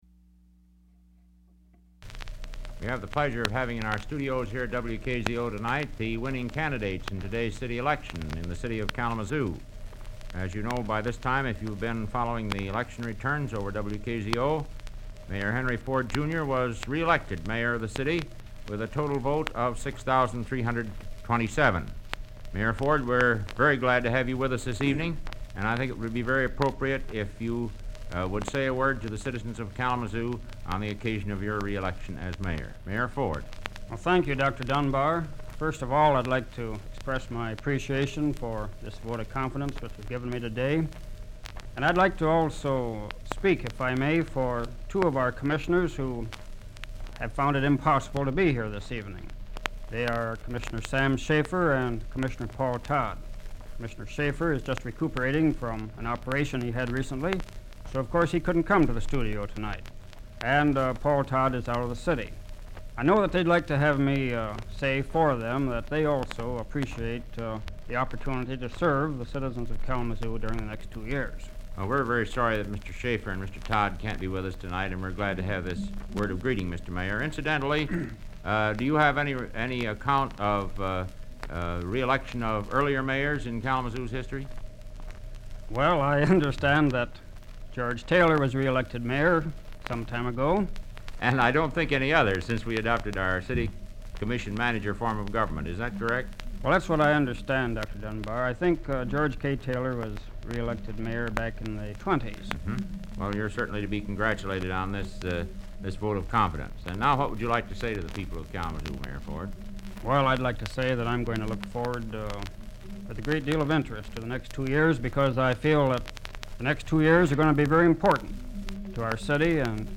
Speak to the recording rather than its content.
Broadcast 1947 November